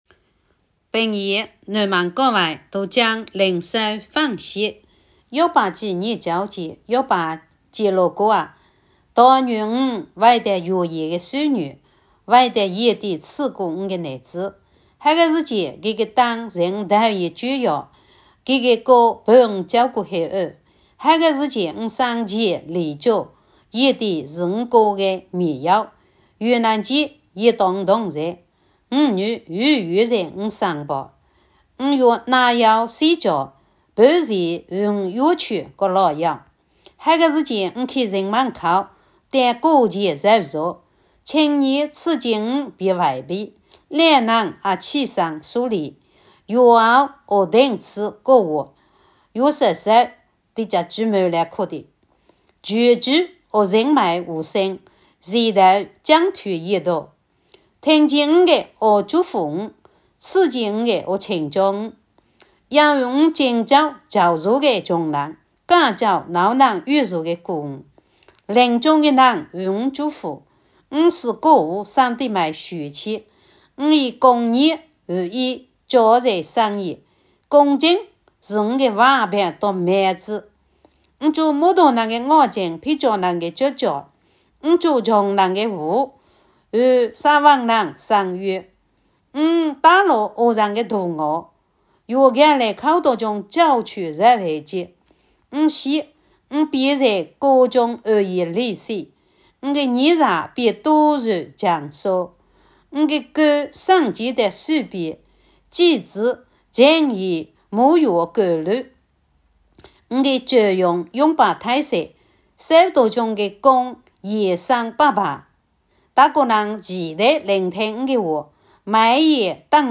平阳话朗读——伯29
平阳南门教会读经灵修分享（伯29）.m4a